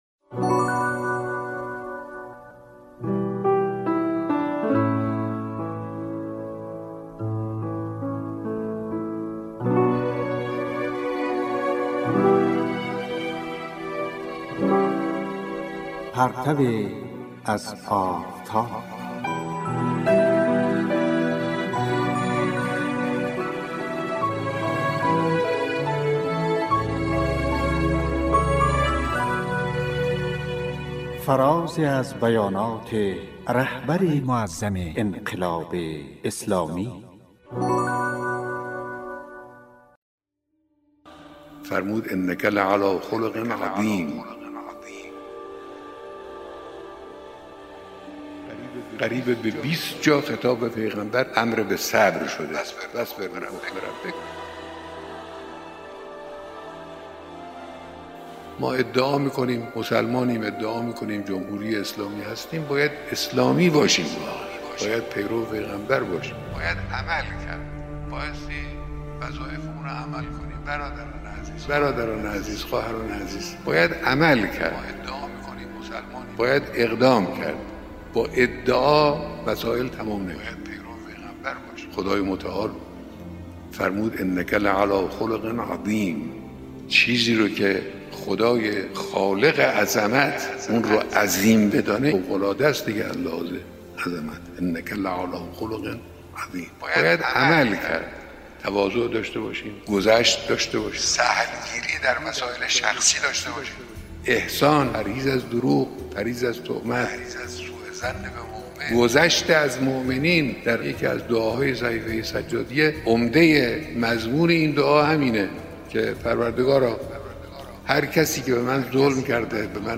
"پرتویی از آفتاب" کاری از گروه معارف رادیو تاجیکی صدای خراسان است که به گزیده ای از بیانات رهبر معظم انقلاب می پردازد.